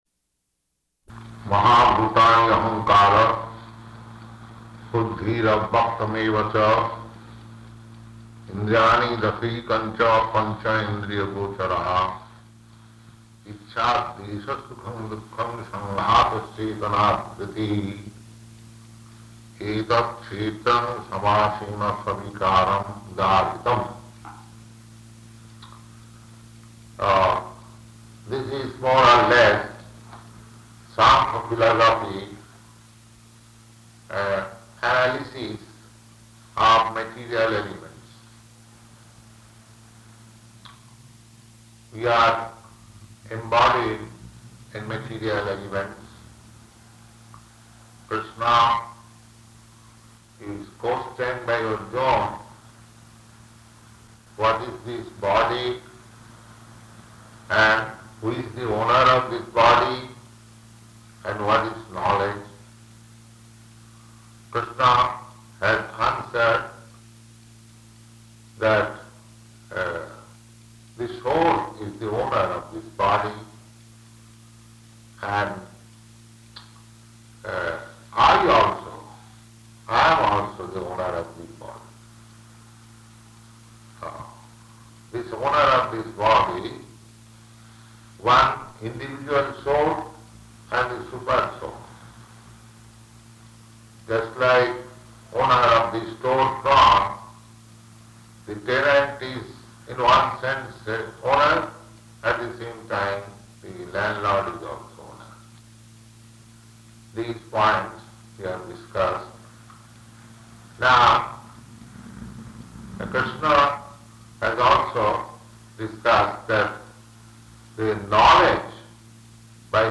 Location: Montreal
[Distorted audio]